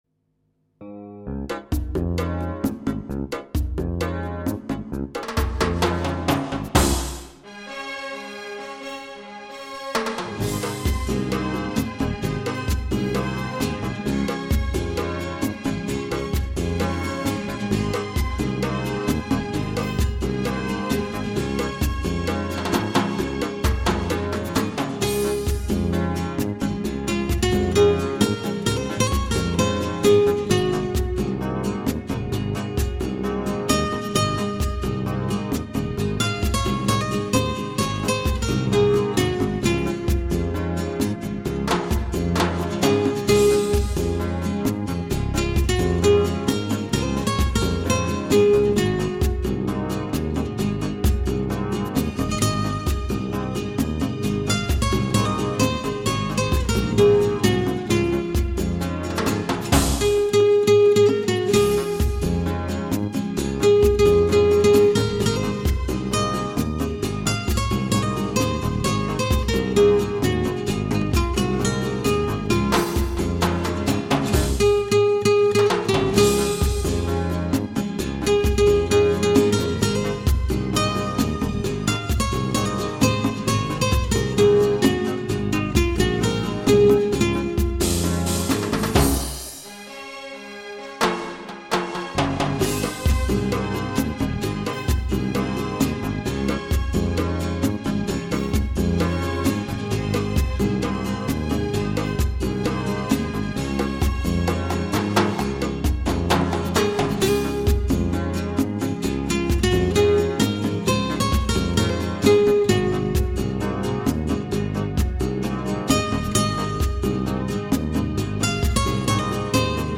西班牙吉他